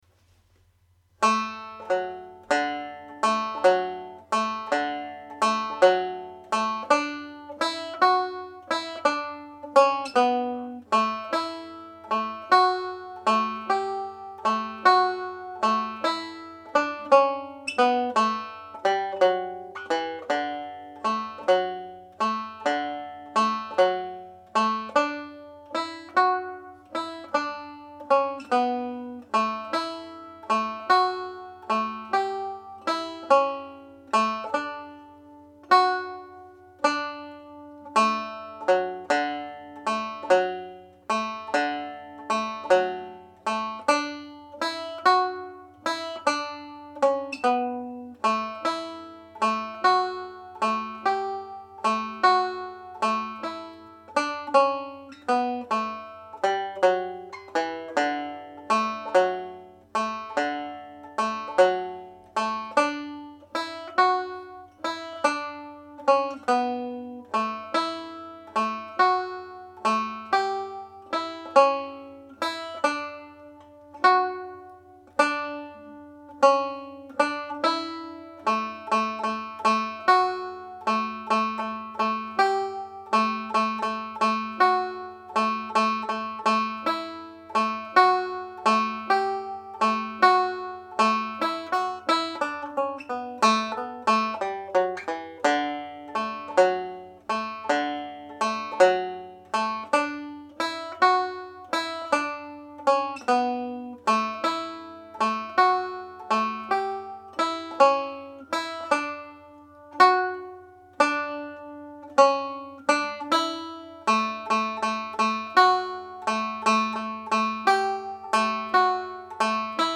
• banjo scale
Hornpipe (D Major)
Harvest-Home-Hornpipe_Slow.mp3